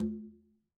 Quinto-HitN_v2_rr1_Sum.wav